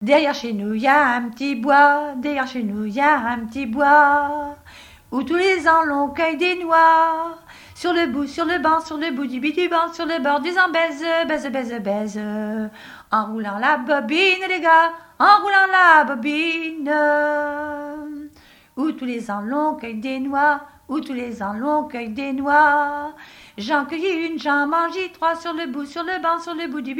danse : ronde : demi-rond
Témoignages sur le mariage et chansons traditionnelles
Pièce musicale inédite